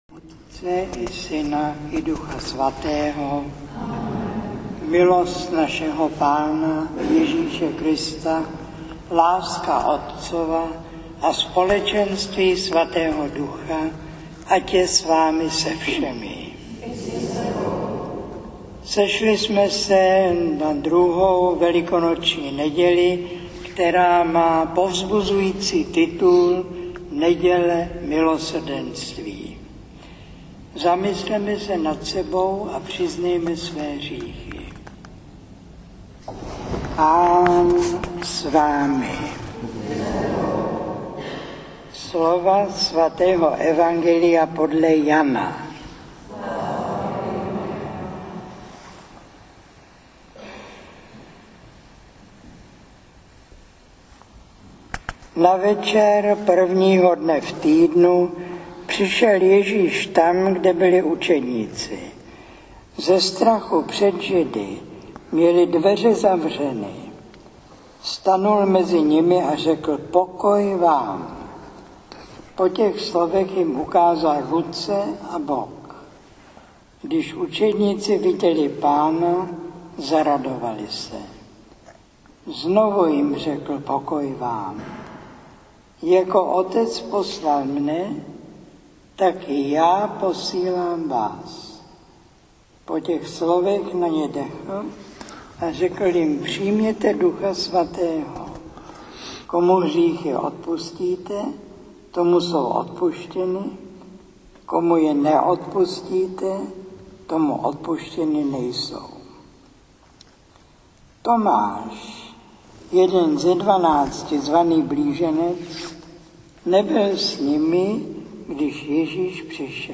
Přehrávání + ukládání: Kázání 84 6.3 MB 192 kb/s mp3 Kázání 84 2.1 MB 64 kb/s mp3 Přehrávání (streaming): není k dispozici Ukládání: není k dispozici Kázání 85 (11 min.)